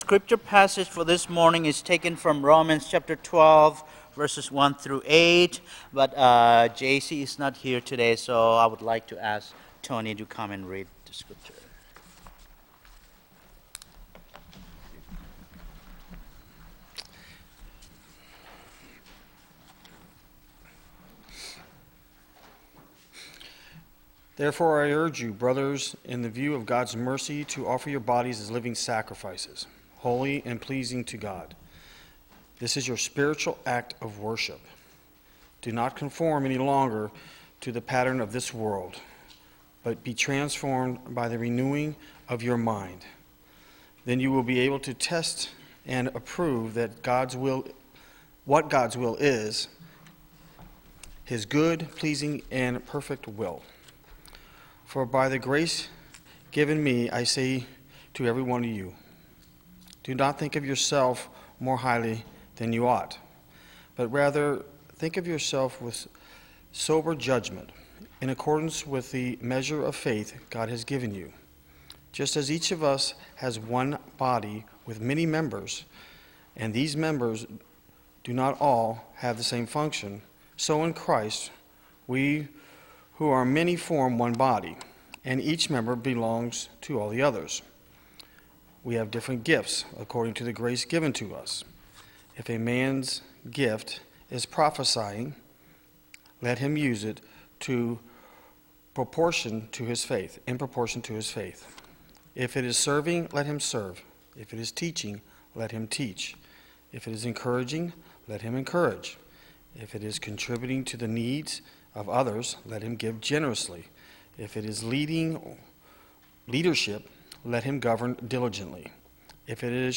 The audio recording of our latest Worship Service is now available.